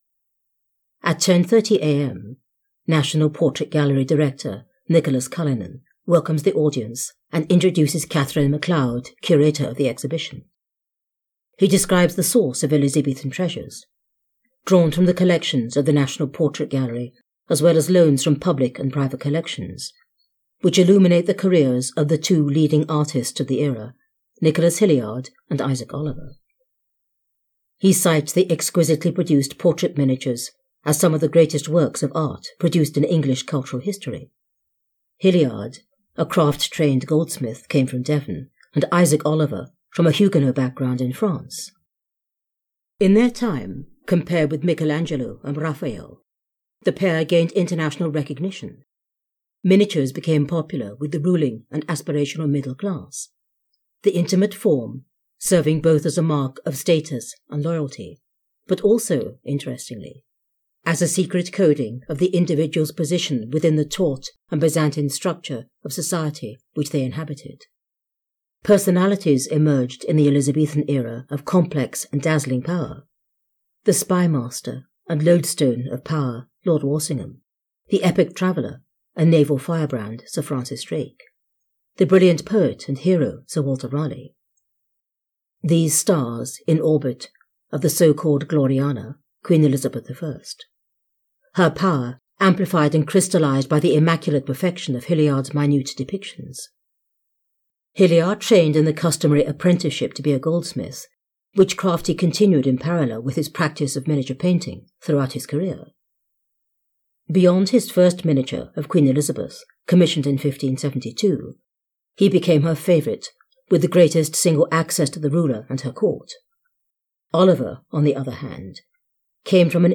A recorded visit to Elizabethan Miniatures during the press view at the National Portrait Gallery London.
The Crown Jewels audiobook is available on Amazon, Audible and iTunes.